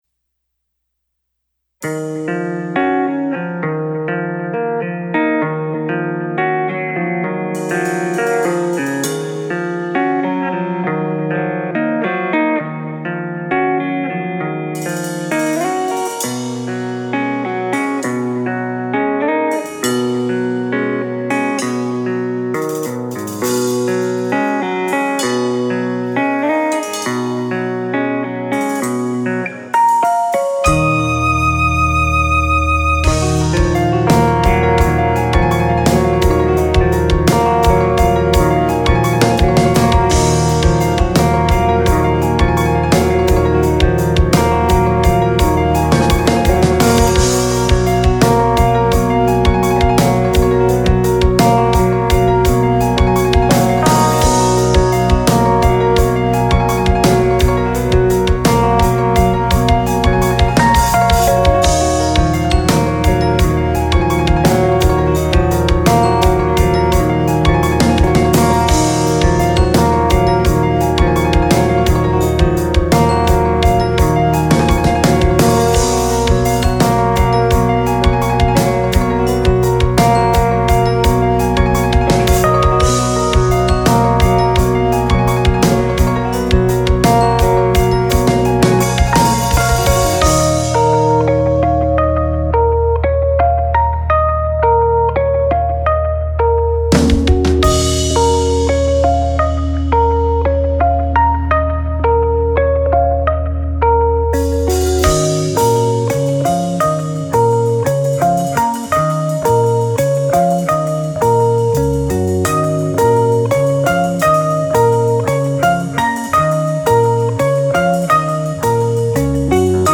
Piano, Bass, Drums, Orchestration
Guitar